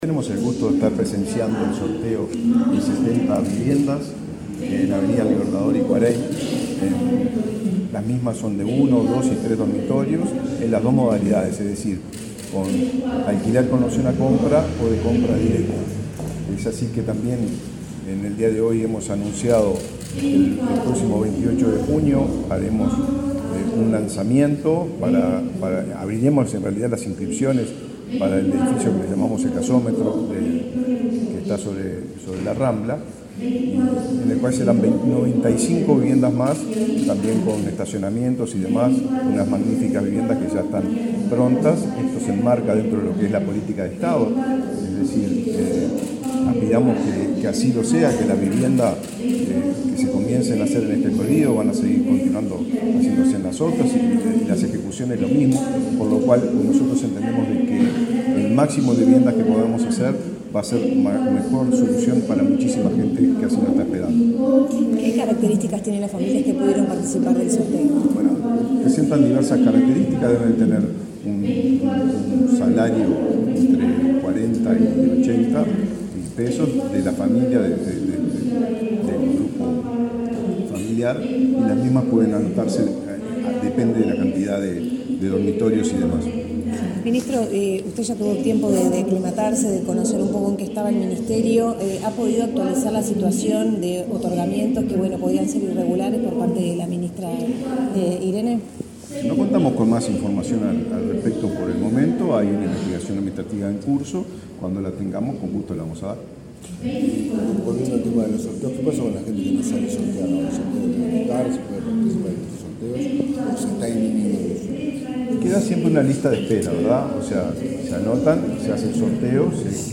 Declaraciones del ministro de Vivienda, Raúl Lozano
Declaraciones del ministro de Vivienda, Raúl Lozano 20/06/2023 Compartir Facebook X Copiar enlace WhatsApp LinkedIn El ministro de Vivienda, Raúl Lozano, presenció el sorteo entre los inscriptos al llamado del edificio ubicado en Av. del Libertador y Cuareim, en Montevideo. Luego dialogó con la prensa.